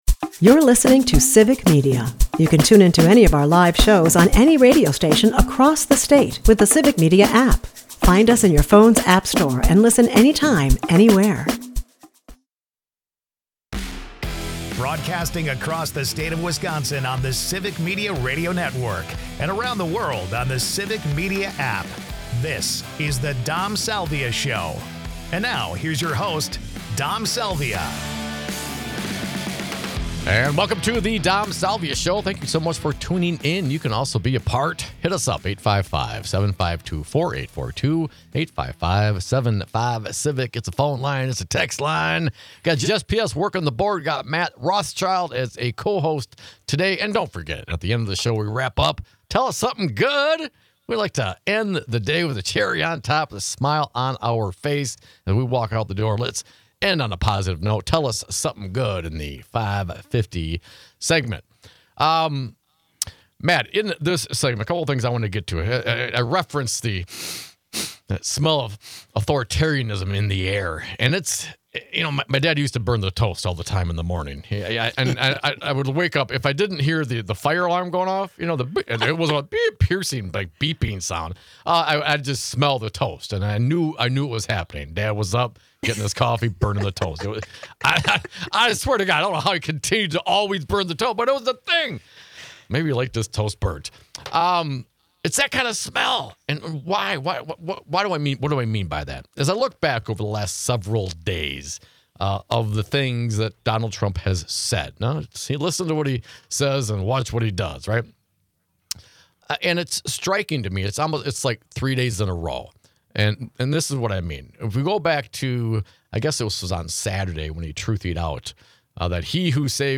Listen to this 5-minute read of June Jordan's own "Poem About My Rights" .